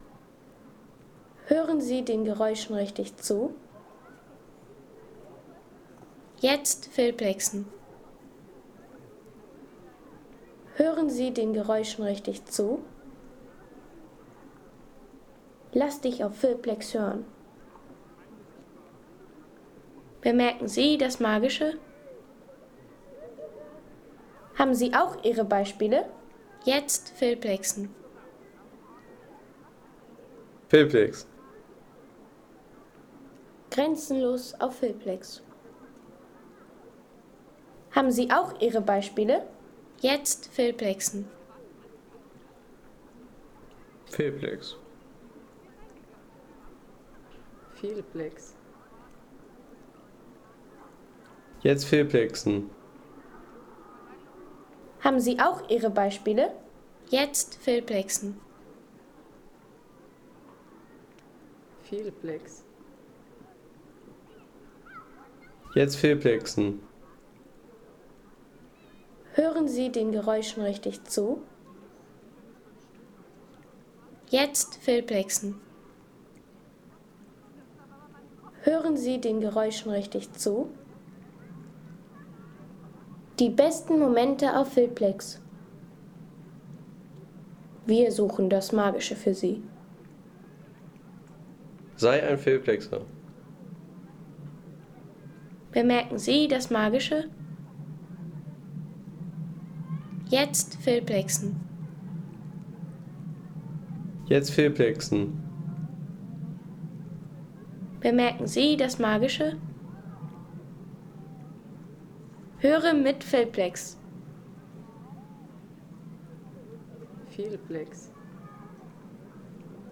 Piburger See | Alpine See-Atmosphäre aus Österreich
Authentische Seeatmosphäre vom Piburger See in Österreich.
Eine ruhige und authentische Klangkulisse vom See in Österreich für Filmszenen, Reisevideos, Dokus und Sound-Postkarten.